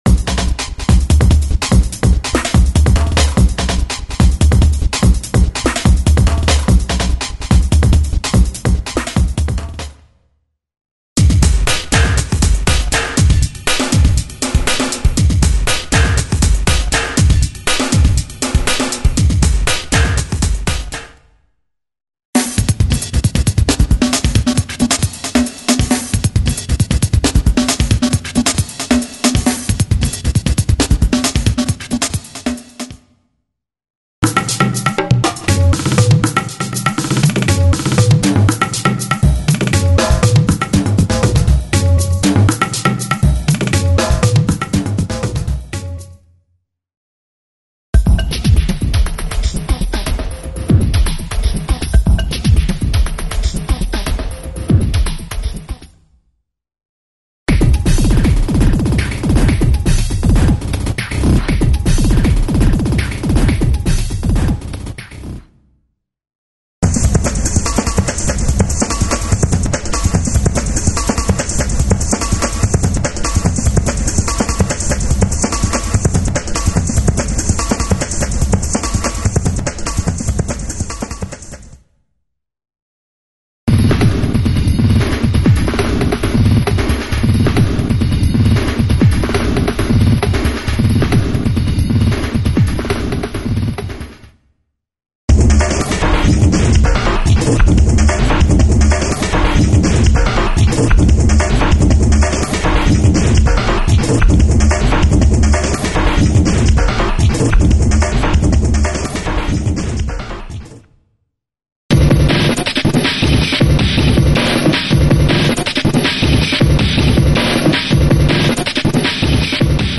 This Drum Loops have a unique touch of feel and sound.
* Rhythm tempos are: 100, 120 ,135, 145, and 160 bpm
from simple to extrem variations.
(100) Electric Bongos Glitch Breaks
(120) Electric Darbuka
(120) Ethno Breaks
(120) Rock
(145) Electro jungle